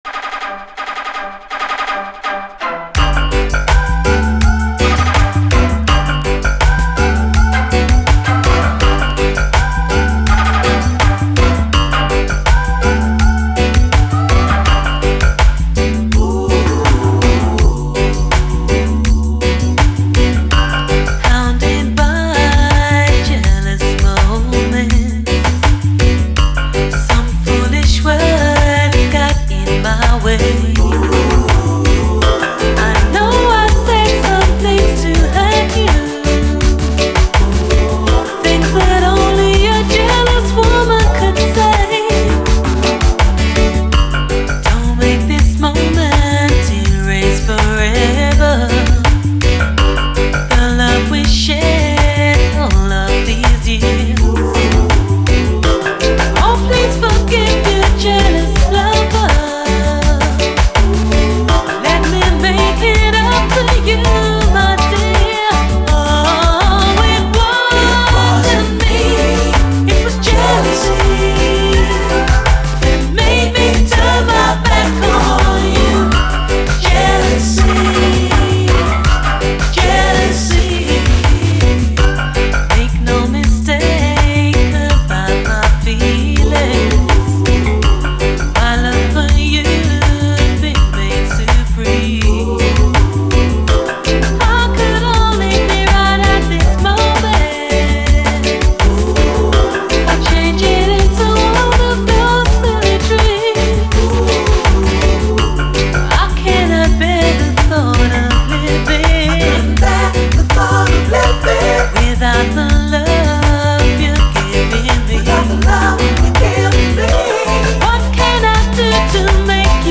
REGGAE
甘い美メロと90’Sフレーヴァーが輝く！